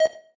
synth3_3.ogg